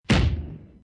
Download Bang sound effect for free.
Bang